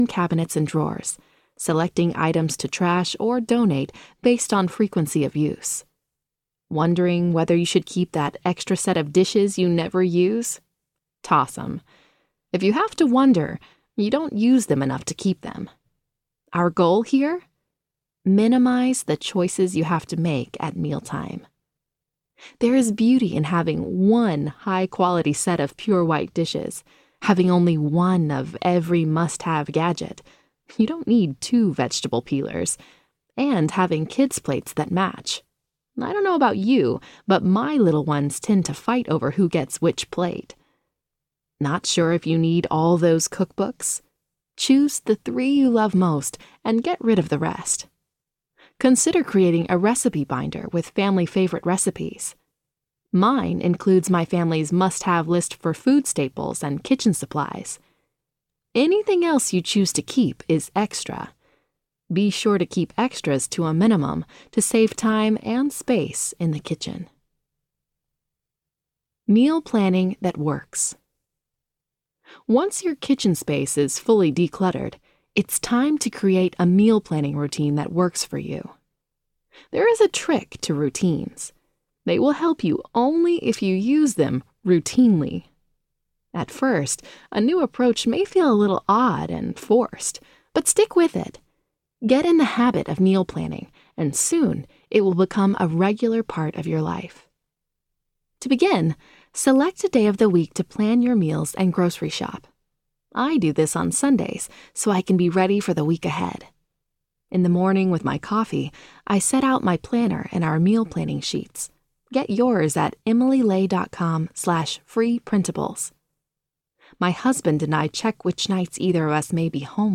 Courage for the Unknown Season Audiobook
Narrator